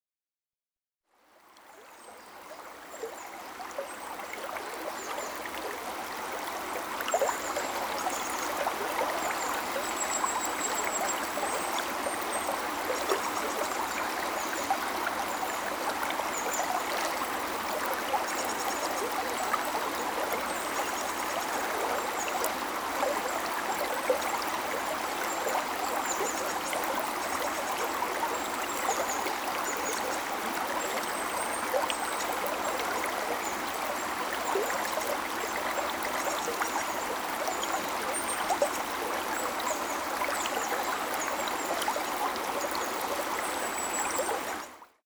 せせらぎ音（※３）を導入し、五感から副交感神経を刺激する
・※３：『せせらぎ〜清流のシンフォニー / ネイチャー・サウンド・ギャラリー』（「晩夏のせせらぎ～白神山地・暗門川（青森県）」／自然音）〔試聴用音源：dlns216_01.mp3〕権利者：株式会社デラ，製品番号：DLNS-216，参照：権利者提供音源